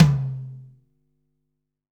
Index of /90_sSampleCDs/300 Drum Machines/Akai MPC-500/1. Kits/Fusion Kit
F Stand Tom 1.WAV